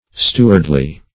Search Result for " stewardly" : The Collaborative International Dictionary of English v.0.48: Stewardly \Stew"ard*ly\, adv. In a manner, or with the care, of a steward.